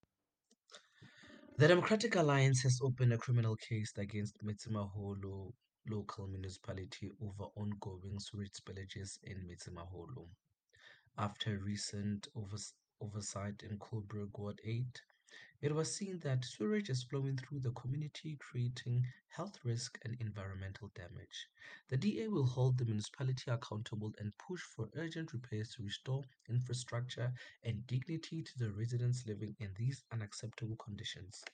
English soundbite by Cllr Teboho Thulo and